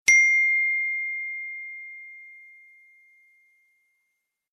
Ringtones Category: iPhone